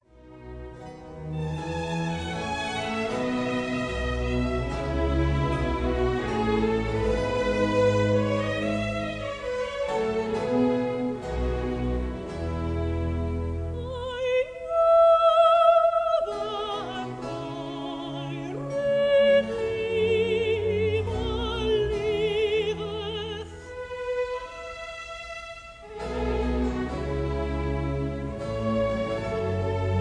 soprano
contralto
tenor
bass
harpischord
organ
Recorded in January 1954 at the Kingsway Hall, London.